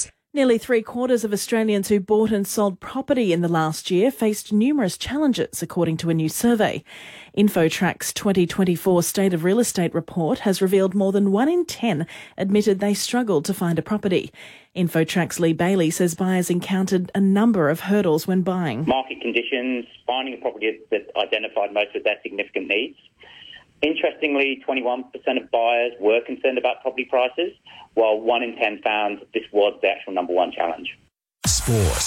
4BC 09:00 News (Weekend)